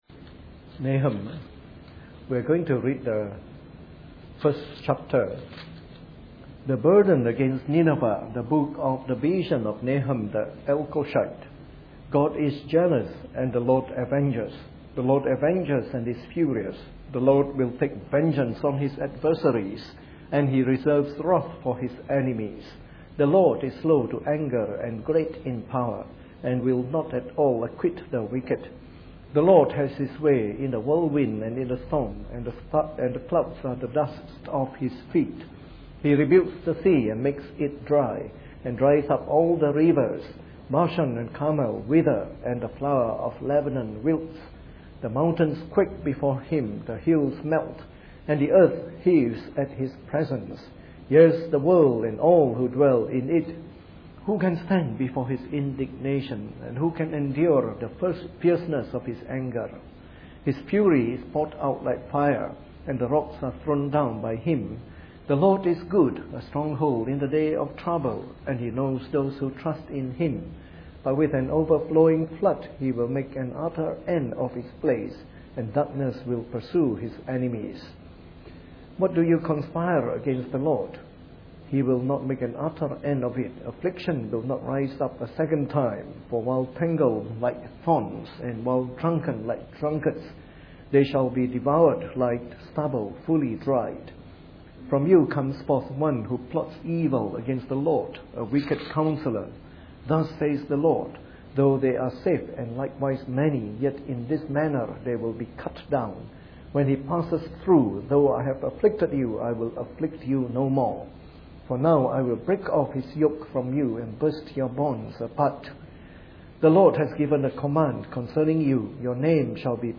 Preached on the 9th of January 2013 during the Bible Study, from our series on “The Minor Prophets.”